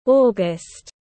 Tháng 8 tiếng anh gọi là august, phiên âm tiếng anh đọc là /ˈɔː.ɡəst/
August /ˈɔː.ɡəst/